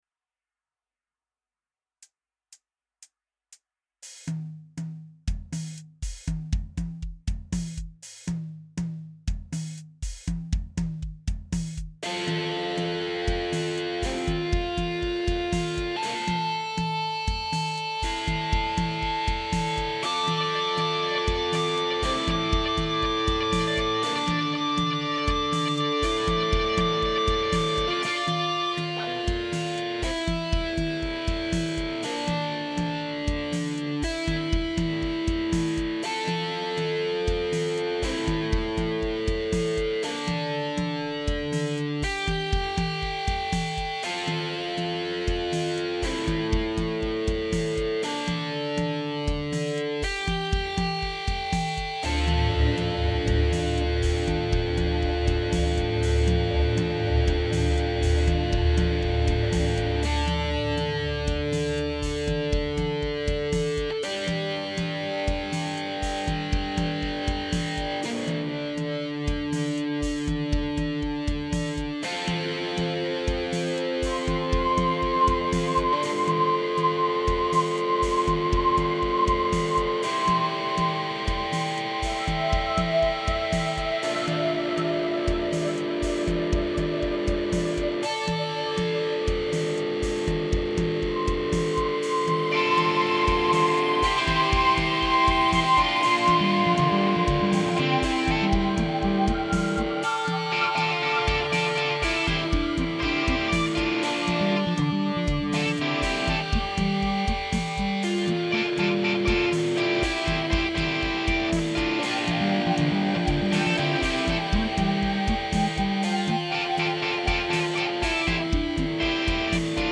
Für die Evolution Infinity Software, wurde eine Gitarre aus den 1980er Jahren gesampled, welche einen Sustainer Tonabnehmer hat.
Dadurch kann ein Ton, oder ein Akkord ewig lange gehalten werden und das auch, wenn der Amp nur auf Zimmerlautstärke aufgedeht ist.
Der eine dient dazu, den Sustainer ein-, oder auszuschalten, der andere Schalter ist ein Wahlschalter, mit dem das normale Sustain, oder ein kippen des Tons in den Obertonbereich gewählt werden kann.